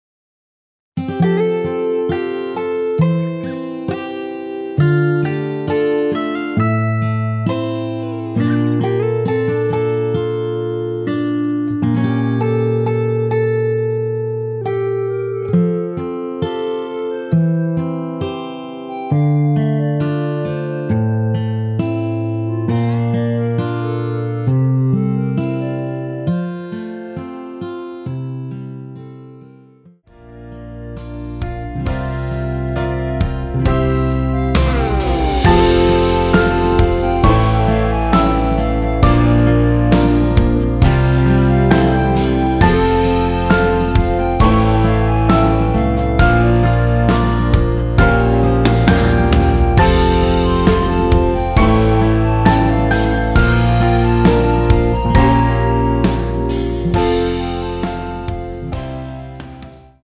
원키에서(+5) 올린 멜로디 포함된 MR 입니다.
멜로디 MR이라고 합니다.
앞부분30초, 뒷부분30초씩 편집해서 올려 드리고 있습니다.
중간에 음이 끈어지고 다시 나오는 이유는